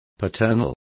Complete with pronunciation of the translation of paternal.